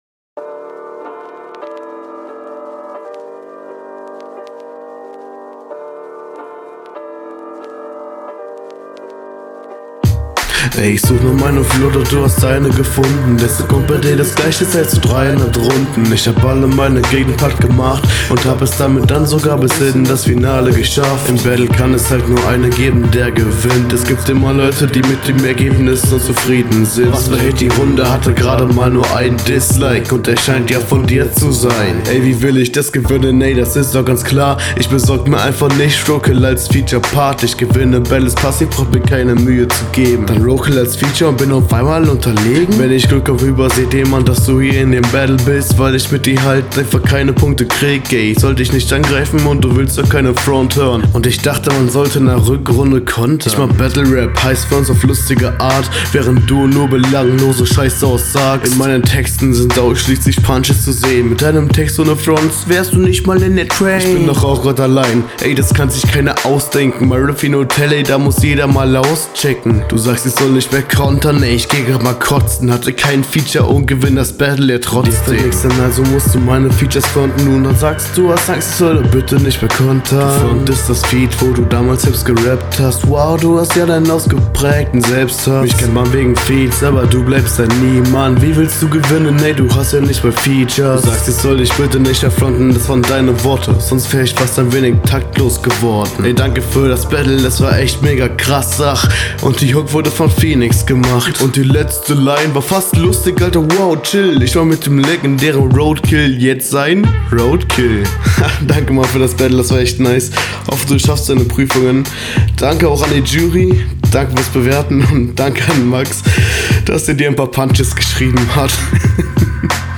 Das Soundbild ist hier auch ganz cool, kommt aber nicht an die HR3 ran - …
Selbes wie bevor mit der Stimme und dem Flow. Konter etwas lasch bzw nicht besondera …